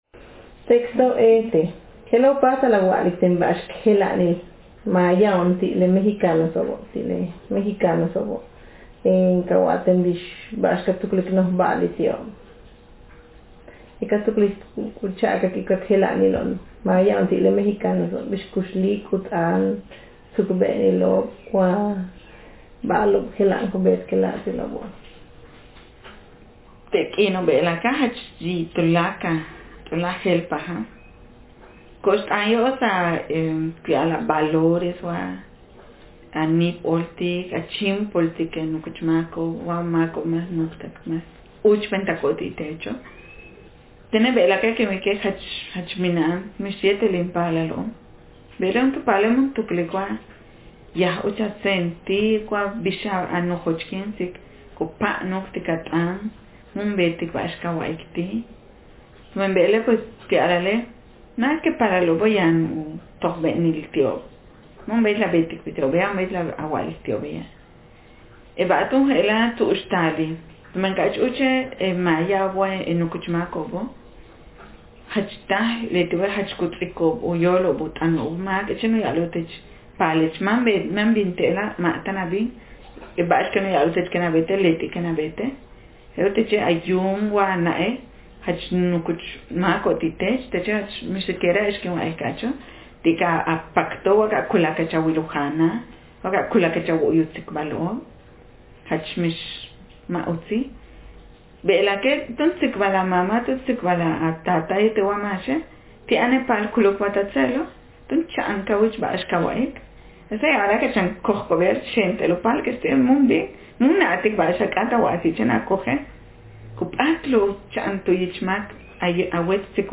Speaker sexf
Text genreprocedural